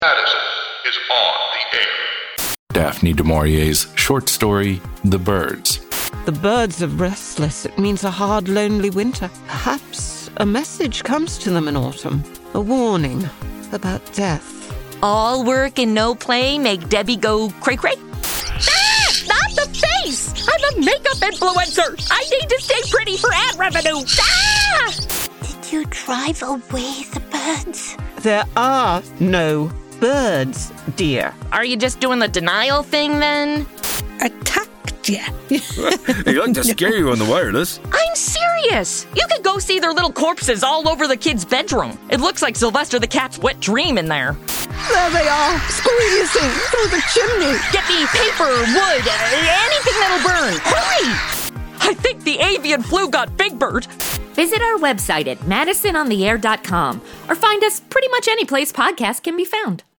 Comedy Audio Drama
Follow Madison Standish, a modern day influencer, as she gets zapped back into the Golden Age of Radio. Actual OTR scripts adapted!